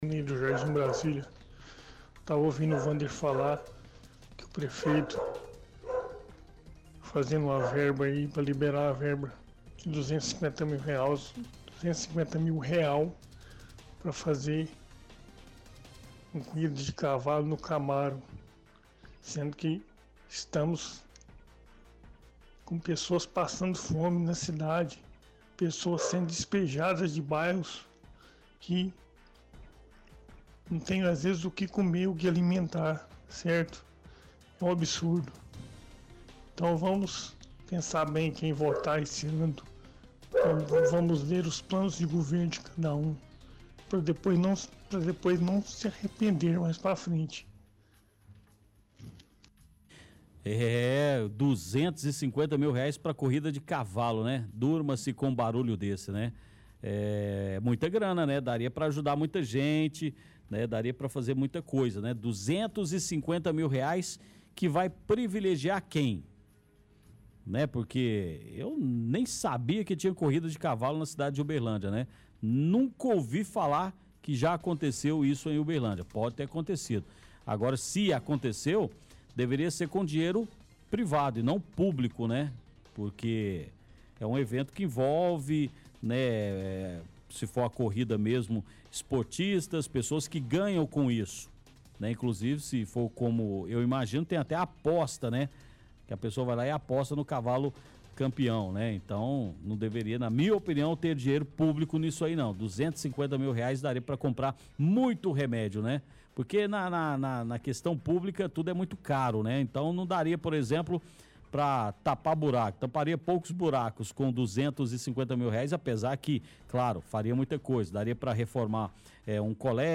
Participação do Ouvinte – Corrida de Cavalos